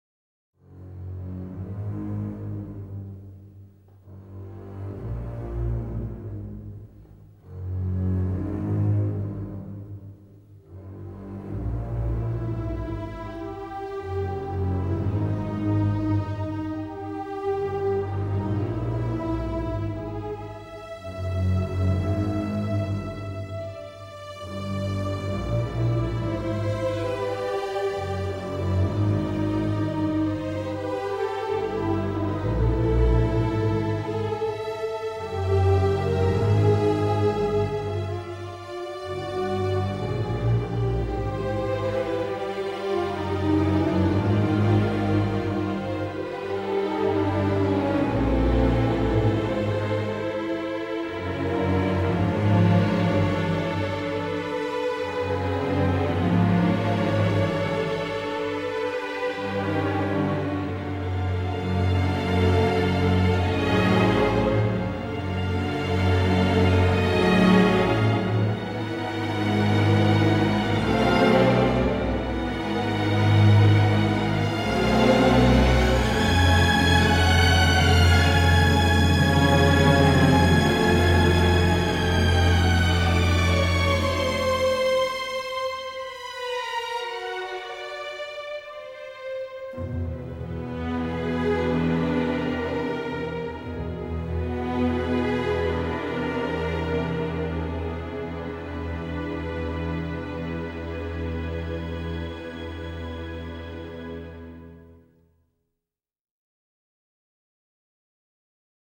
Passion, violence, folie !